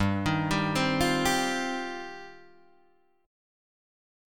G7sus4#5 Chord